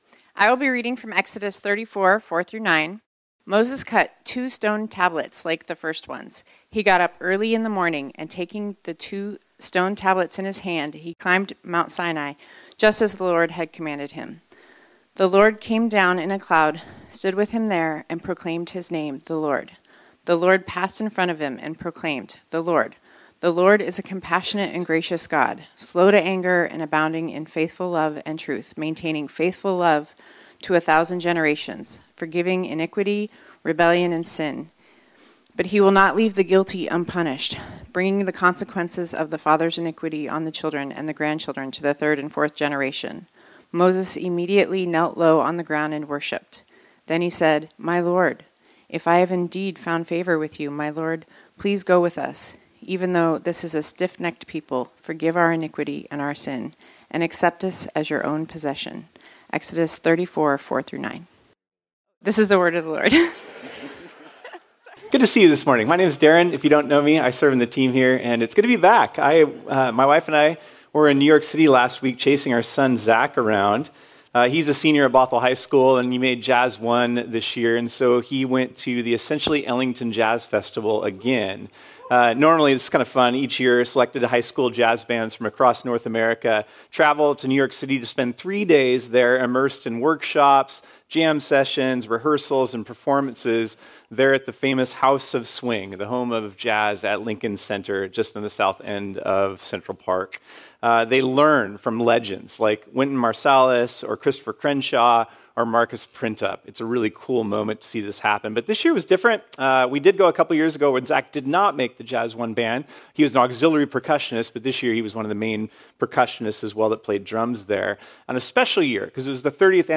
sermon
This sermon was originally preached on Sunday, May 18, 2025.